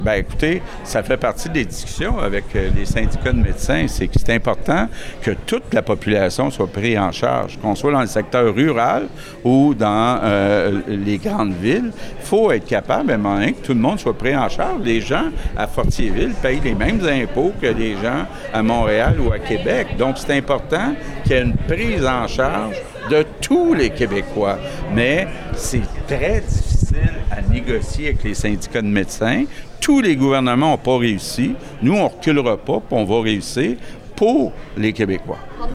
Le premier ministre était présent lundi aux célébrations de la Journée de reconnaissance policière à l’École de police de Nicolet.